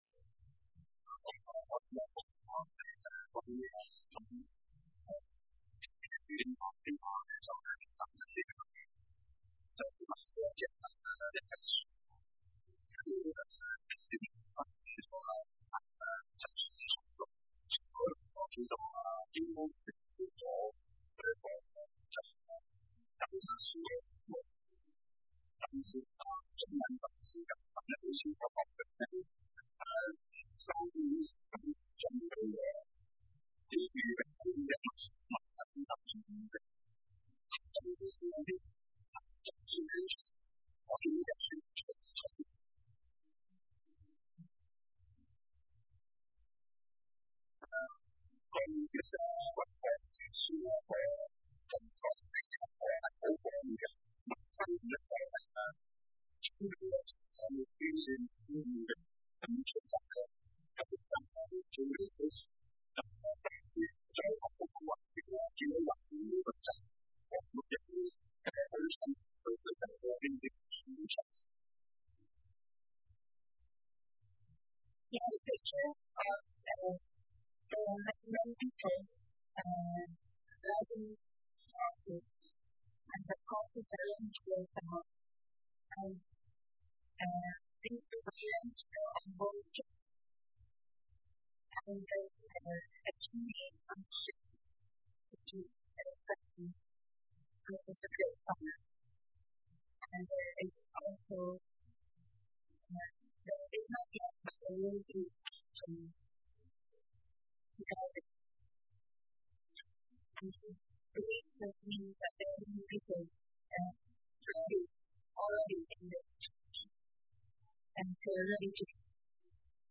The sermon transcript provided is not coherent and does not contain any clear message or topic.